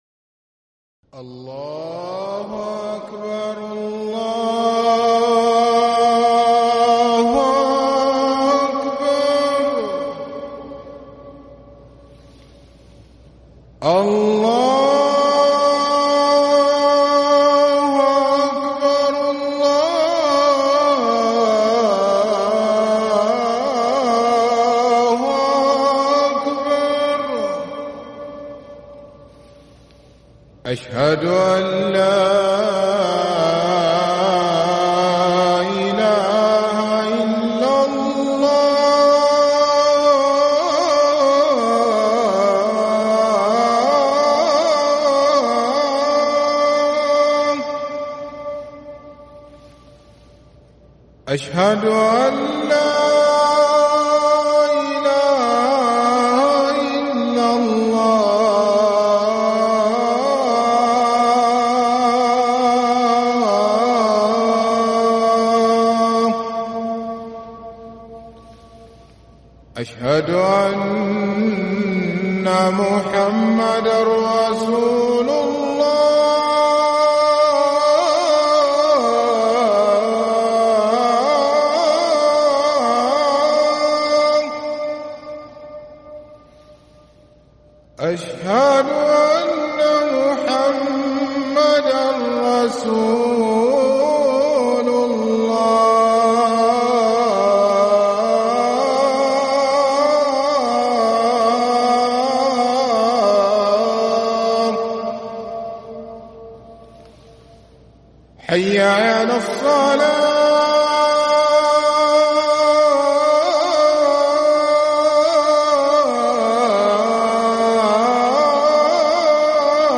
اذان الفجر للمؤذن سعيد فلاته الثلاثاء 1 محرم 1443هـ > ١٤٤٣ 🕋 > ركن الأذان 🕋 > المزيد - تلاوات الحرمين